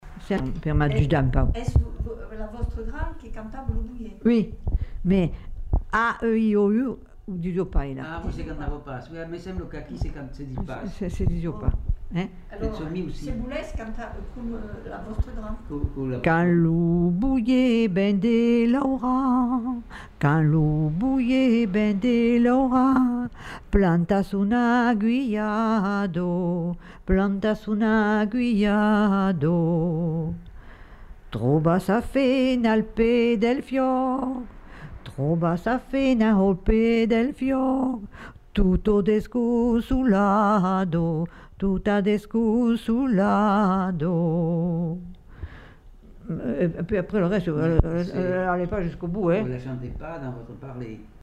Lieu : Lodève
Genre : chant
Effectif : 1
Type de voix : voix de femme
Production du son : chanté